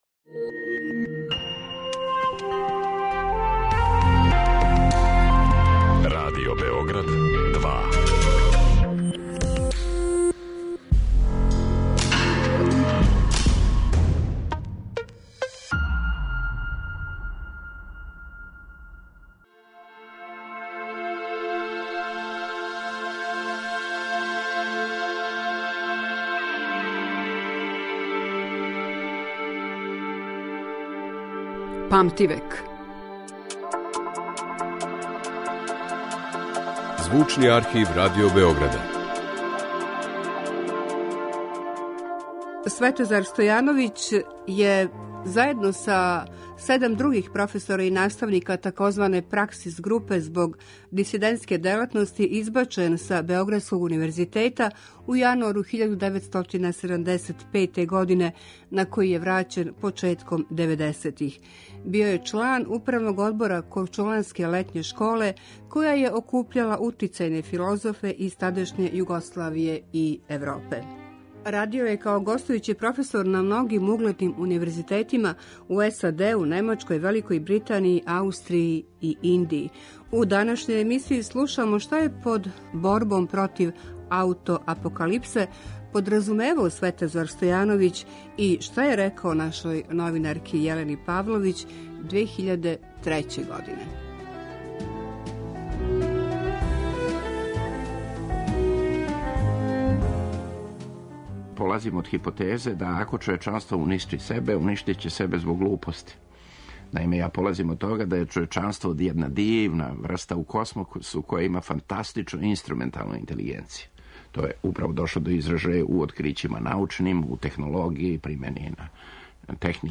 Емисија која ће покушати да афирмише богатство Звучног архива Радио Београда, у коме се чувају занимљиви, ексклузивни снимци стварани током целог једног века, колико траје историја нашег радија.